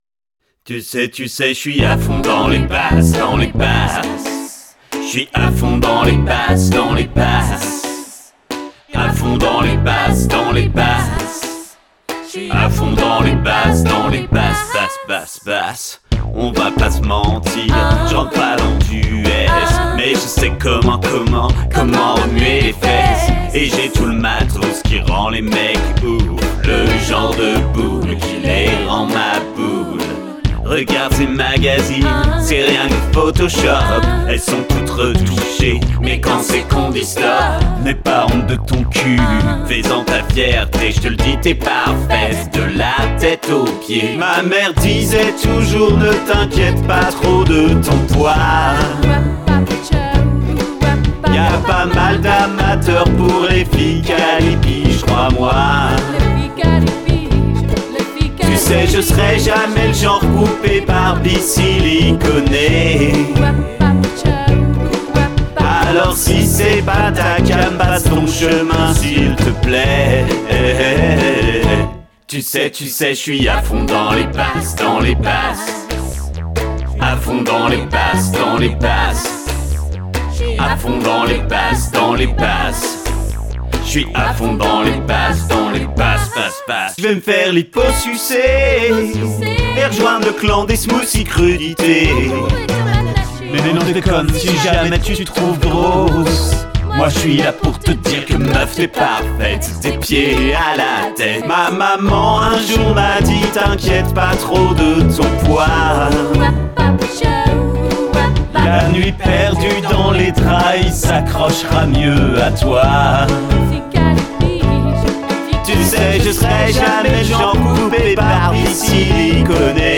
# ukuleleCover
Reprise au ukulélé, piano, basse, percussions.